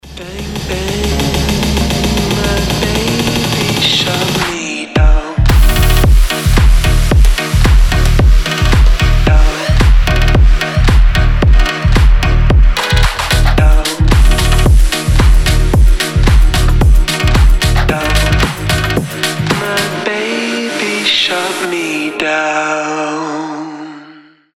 • Качество: 320, Stereo
Electronic
EDM
басы
Bass House